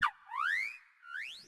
Cri de Superdofin dans sa forme Ordinaire dans Pokémon Écarlate et Violet.